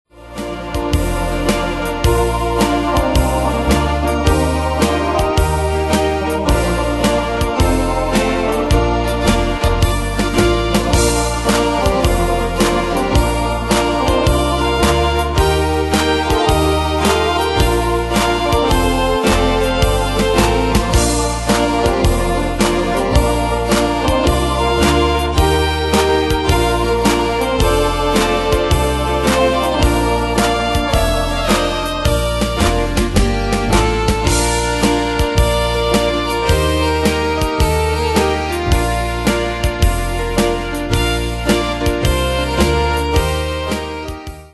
Demos Midi Audio
Danse/Dance: Bavarois Cat Id.
Pro Backing Tracks